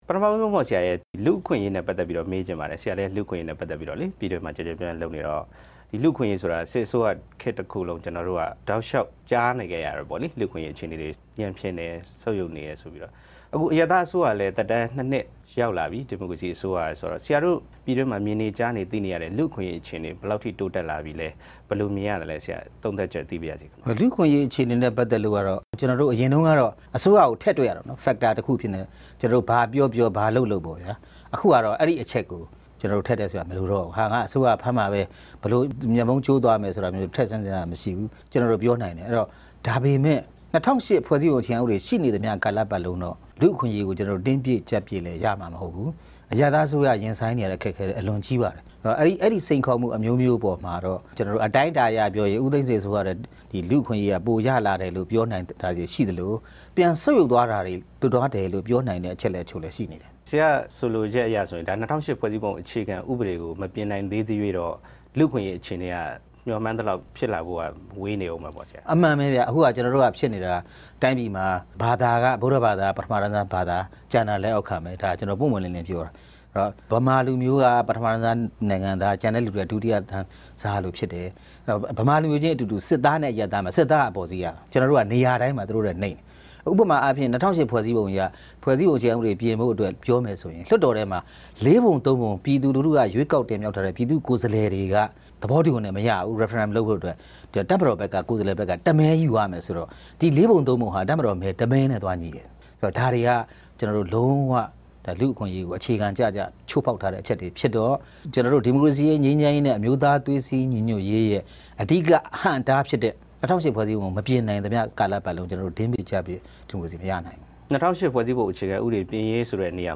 RFA တွေ့ဆုံမေးမြန်းခန်း
ဝါရှင်တန်ဒီစီမြို့တော် RFA ရုံးချုပ်မှာ တွေ့ဆုံမေးမြန်းထားပါတယ်။